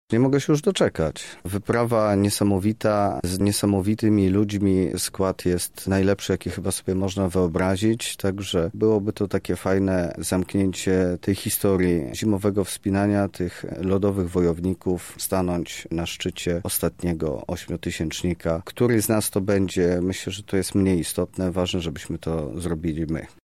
A o emocje towarzyszące przygotowaniom pytał nasz reporter.